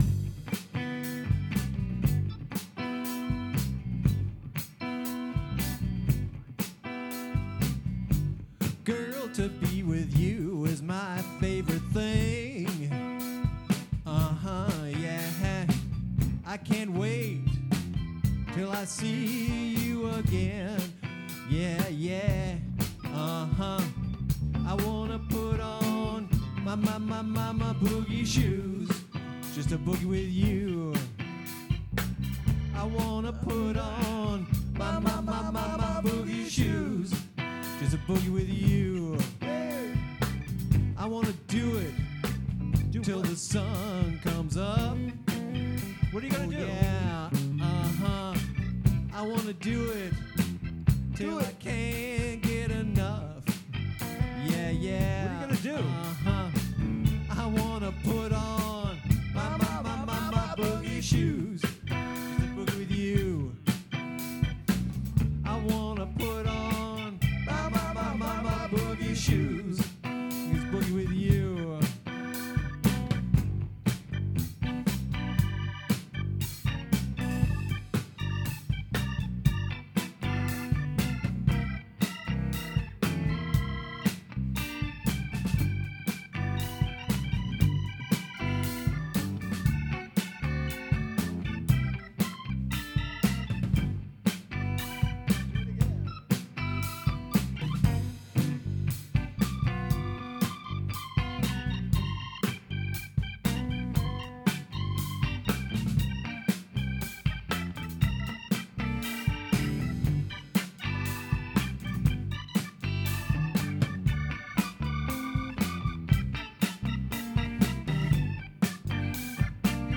guitarist
bassist
drummer
Boogie Shoes KC and the Sunshine Band Rehearsal, 4/23/2025